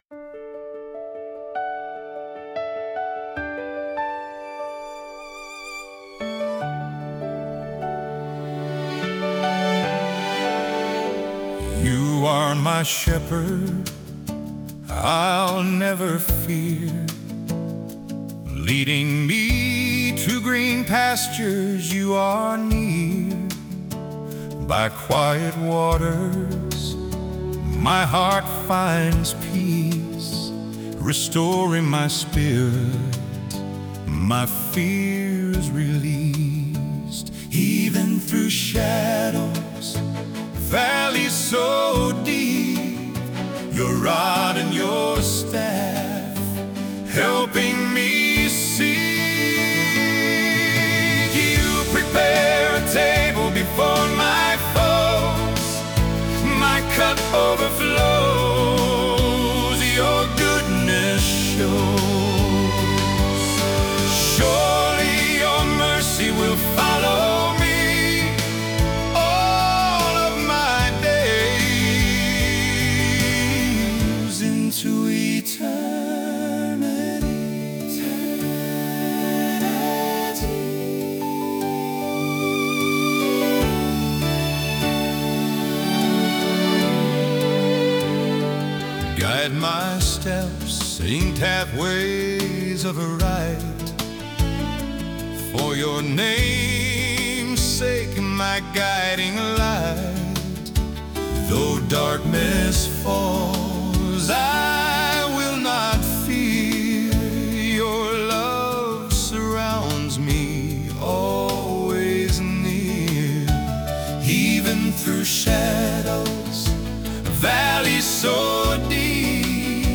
Encouraging and emotional Songs